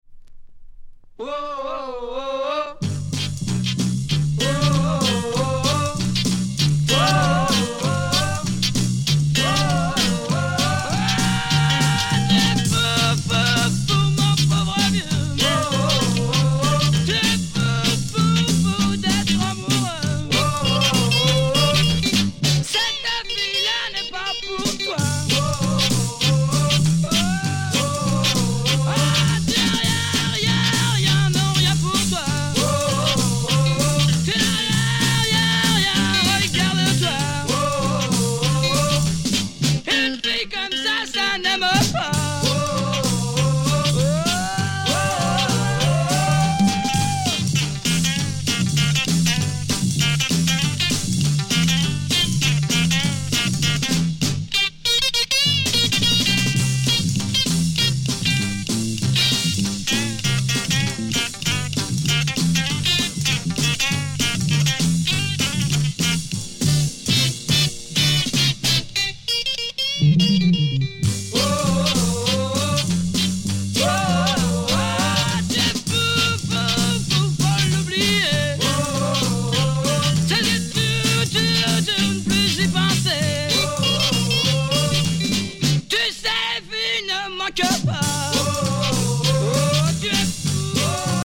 Teen French beat punk 66